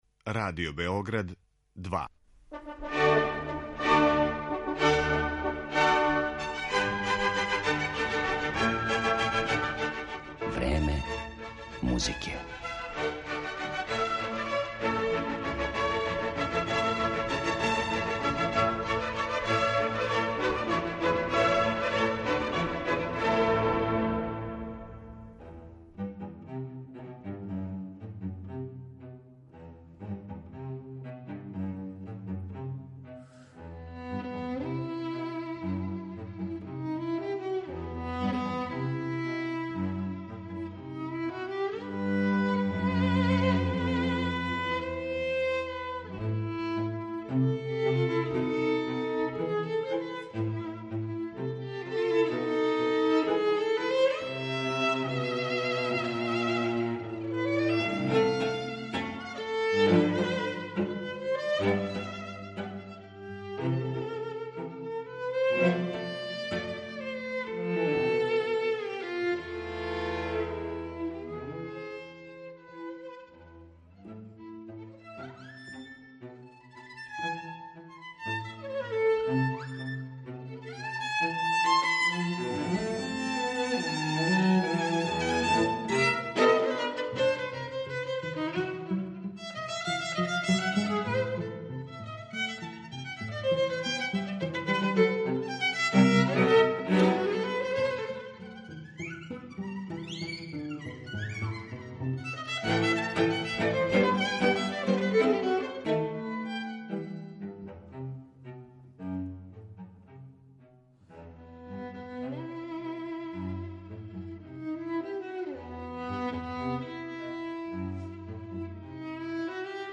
Разговор са виолинистом
Емитоваћемо снимке са најновијих компакт-дискова овог занимљивог уметника.